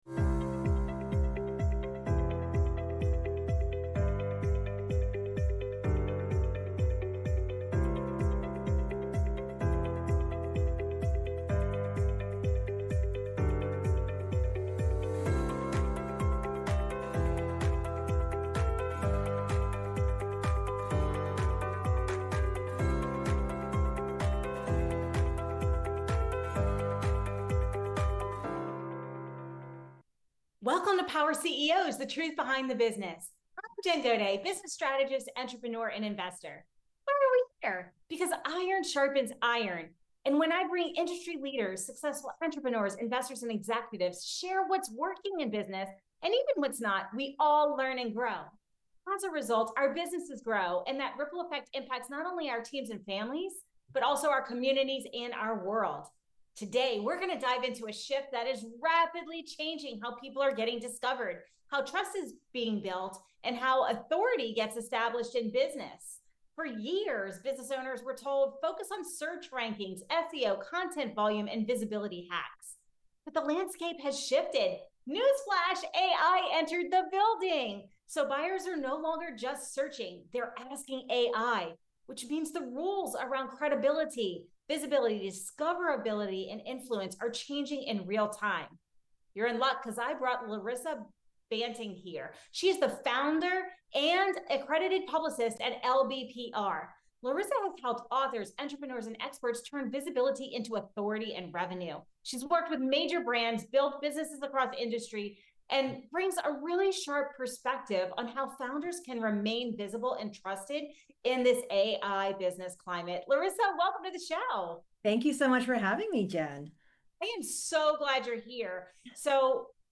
The conversation focuses on the shift from traditional SEO to AI-driven search, where buyers are no longer browsing websites but relying on AI-generated answers to find experts and make decisions.